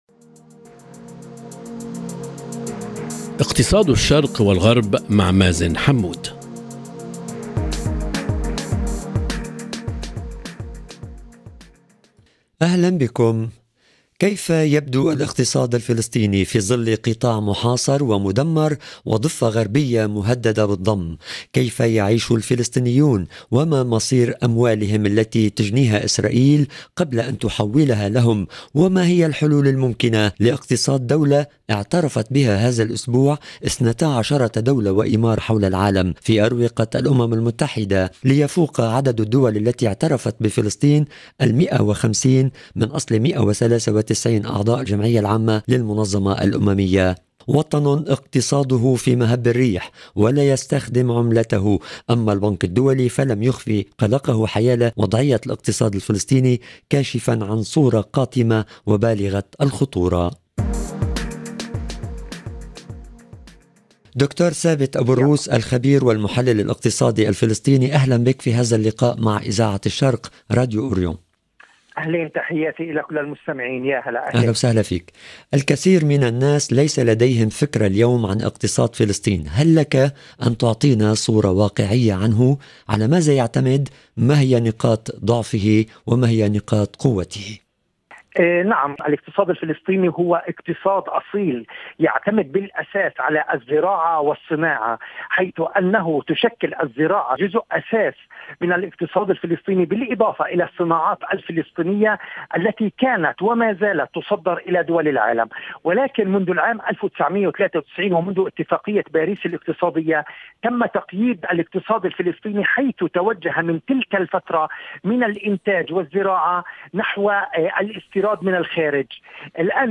ضيف الحلقة الخبير والمحلل الاقتصادي